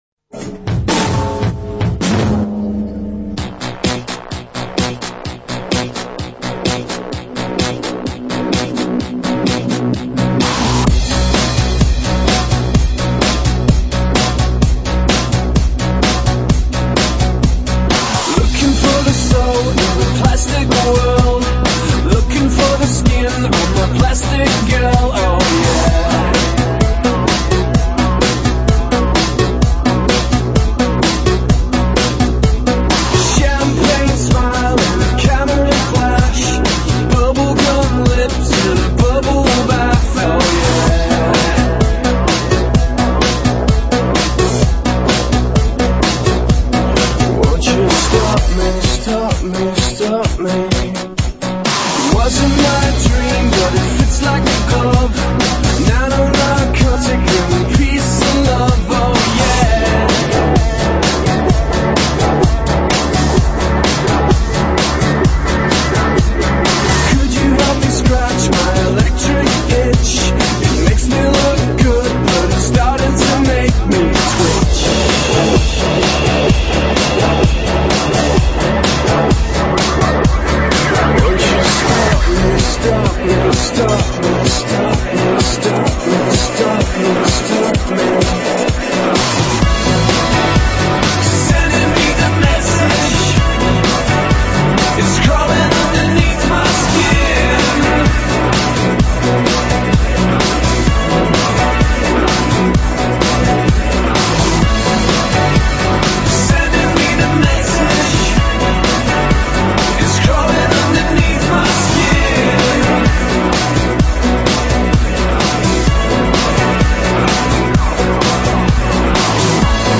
DNB